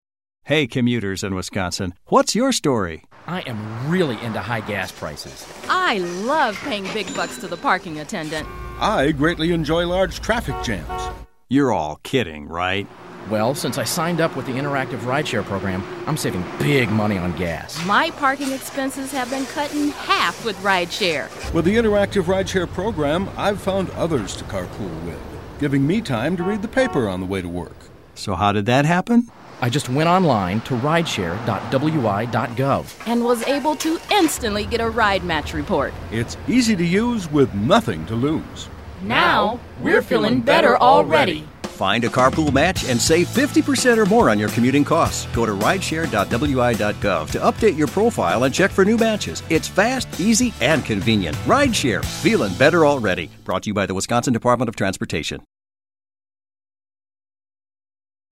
Radio ad - Group uses park and ride lot - After updating their profile and checking for new matches, Michael and Jessica add Linda to their carpool and pick her up at a park and ride lot.